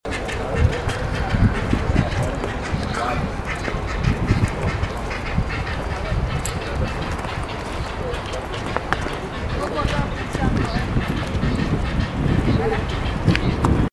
Great Egret (Ardea alba)
Life Stage: Adult
Location or protected area: Lago de Regatas
Condition: Wild
Certainty: Photographed, Recorded vocal